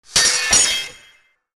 sword-clash2
Category: Sound FX   Right: Personal
Tags: sword